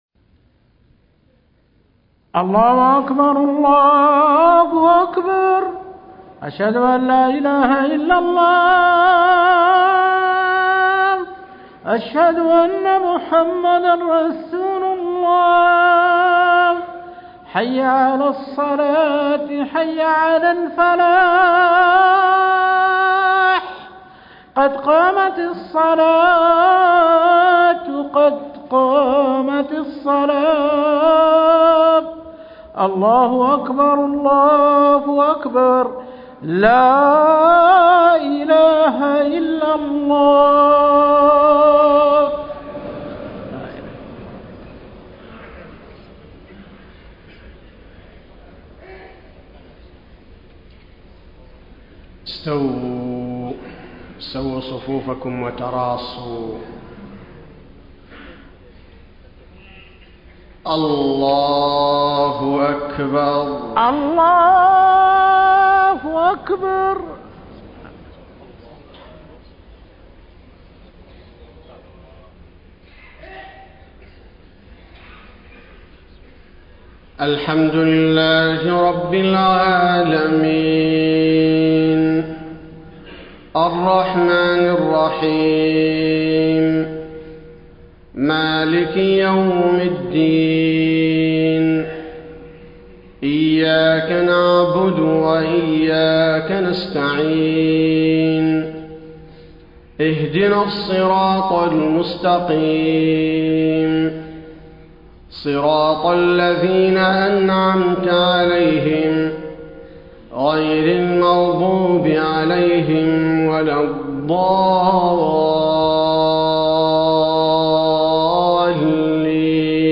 صلاة الفجر 5 - 4 - 1434هـ من سورة الرحمن > 1434 🕌 > الفروض - تلاوات الحرمين